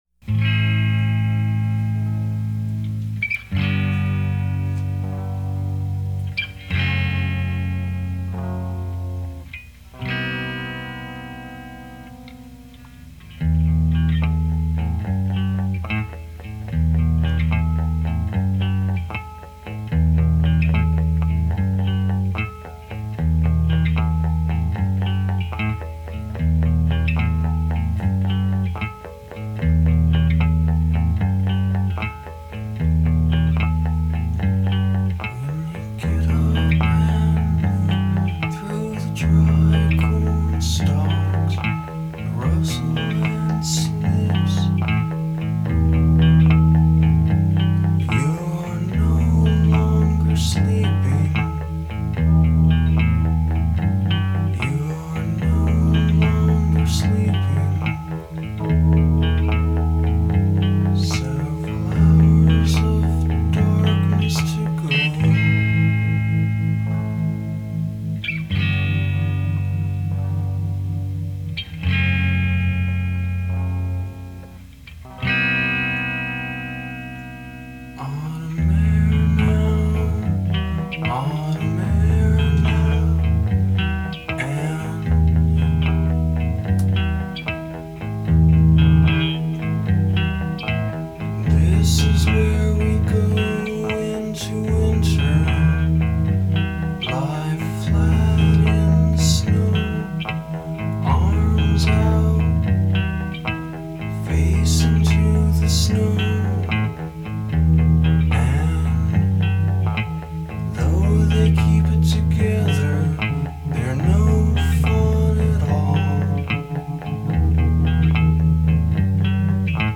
Rhodes
That hiss, is the amp and guitar